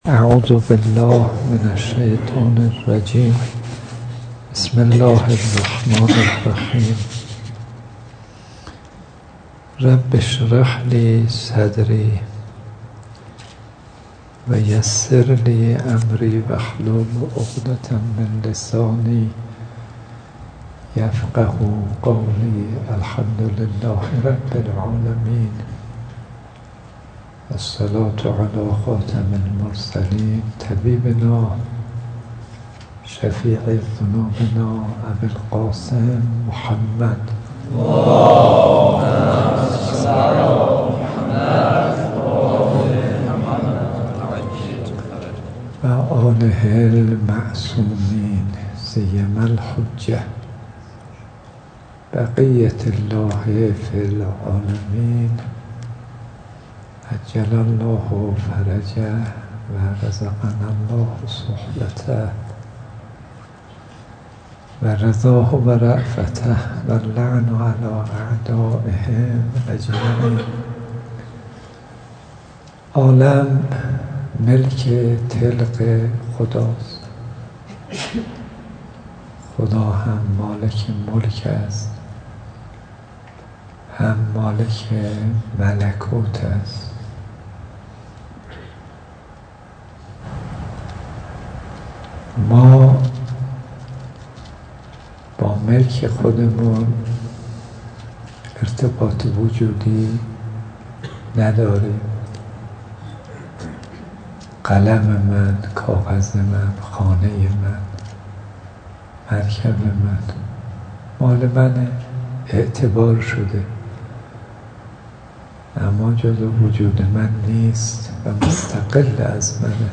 درس اخلاق امروز شنبه 11 آذر 1396 آیت الله صدیقی که با حضور مدیریت، معاونین، اساتید و طلاب حوزه علمیه امام خمینی (ره) در مسجد این حوزه برگزار گردید.